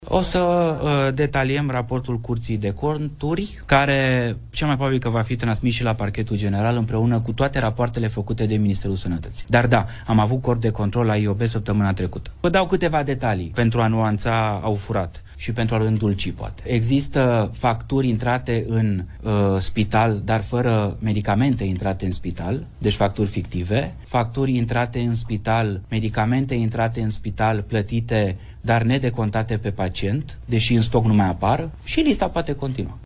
Ministrul demisionar Alexandru Rogobete: Raportul Curții de Conturi va fi transmis și la Parchetul General